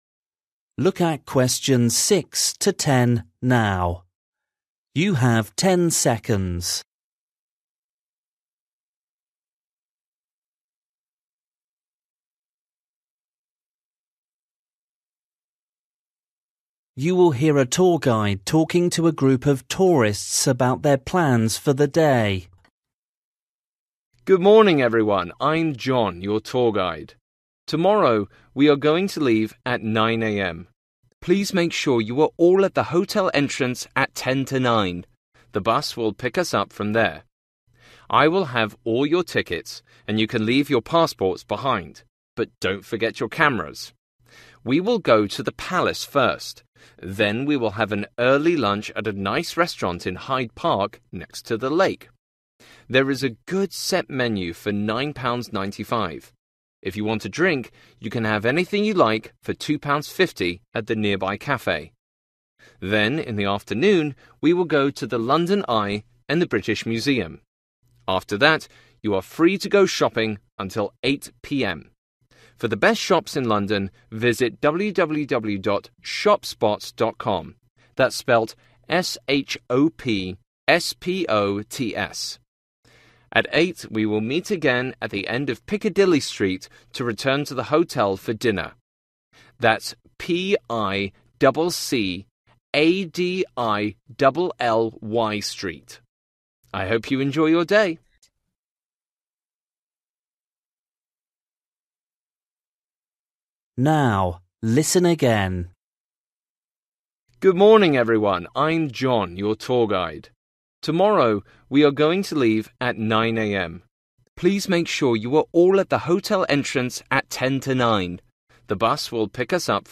You will hear a tour guide talking to a group of tourists about their plans for the day.